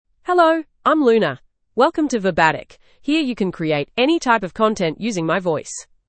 FemaleEnglish (Australia)
Luna is a female AI voice for English (Australia).
Voice sample
Female
Luna delivers clear pronunciation with authentic Australia English intonation, making your content sound professionally produced.